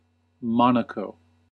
^ /ˈmɒnək/
MON-ə-koh; French: [mɔnako]; Italian: [ˈmɔːnako]; Monégasque: Mùnegu [ˈmuneɡu]; Occitan: Mónegue [ˈmuneɣe]
En-us-Monaco.ogg.mp3